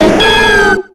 Audio / SE / Cries / MEGANIUM.ogg